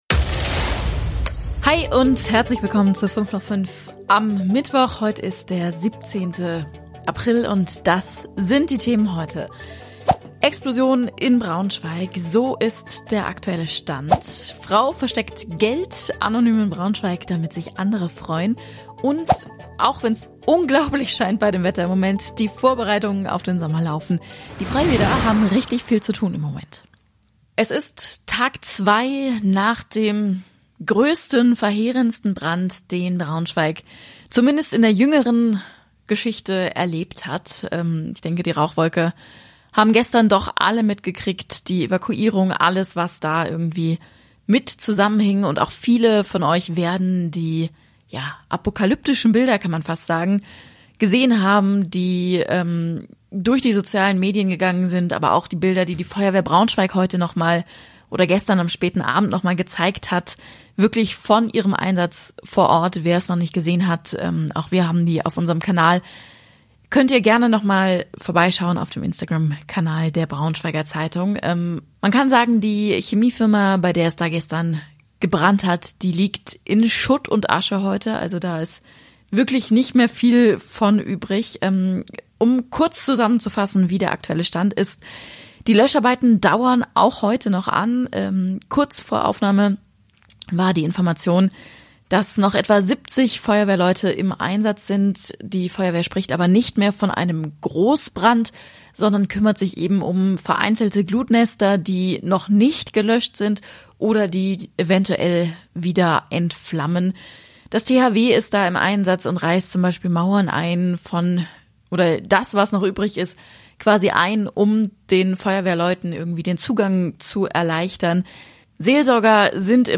Die Themen heute: Nach dem Brand in BS: Das wissen wir bisher. Hier berichten Anwohner.